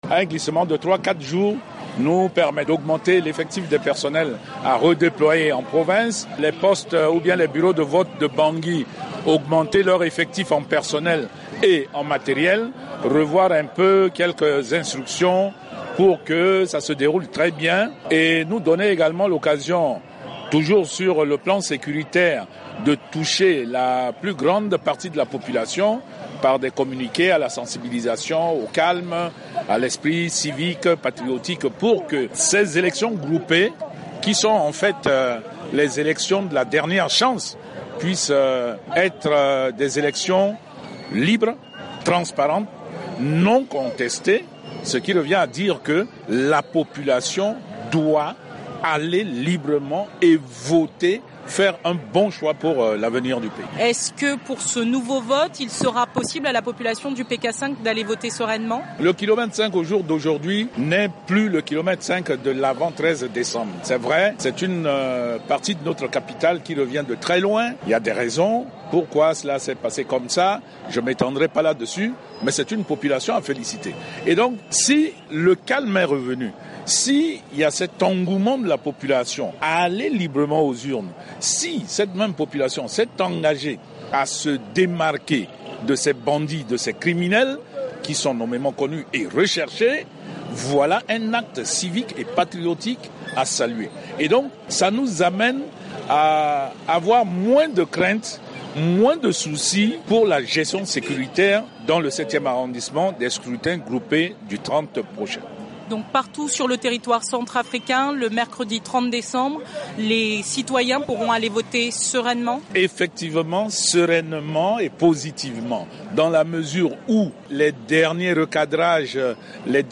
Dominique Saïd Panguindji, ministre centrafricain de la Sécurité